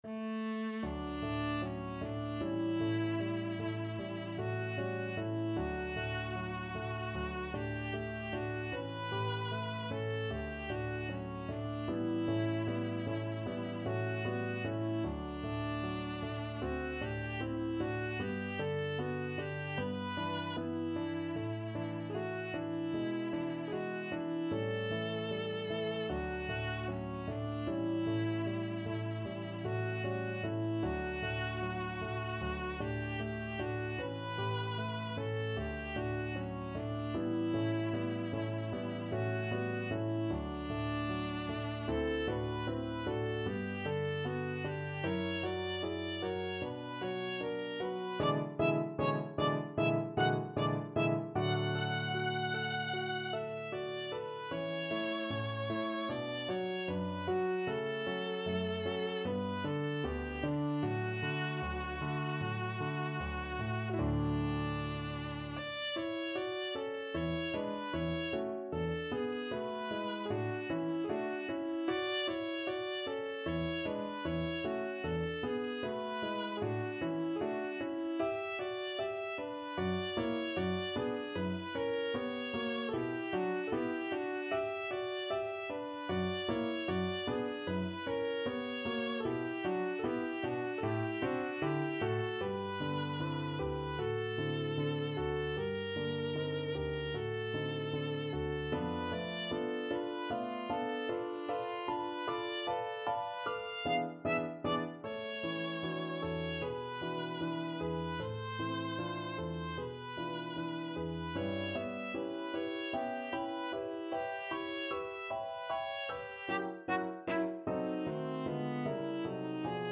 2/4 (View more 2/4 Music)
Lento ma non troppo = c.76
Classical (View more Classical Viola Music)